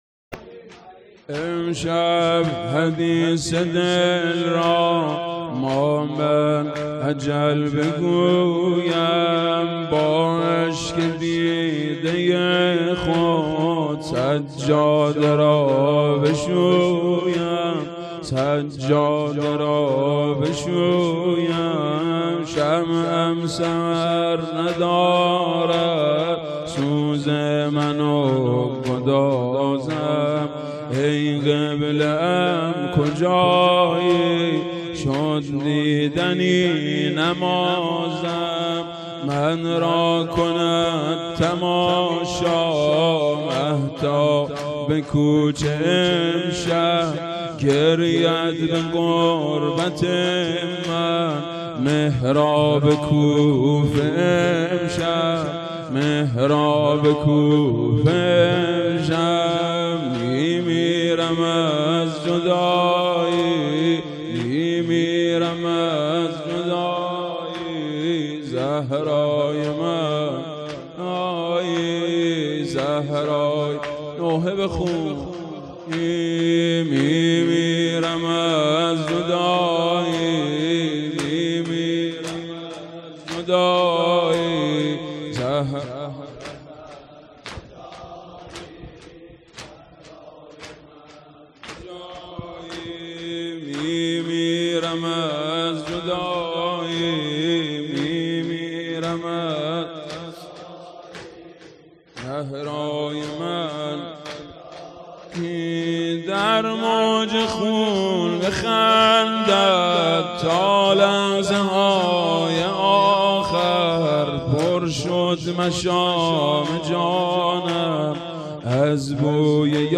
زمینه شهادت امیرالمومنین ع هیئت محبین العباس و فاطمیون و هیئت الرضا ملاثانی.mp3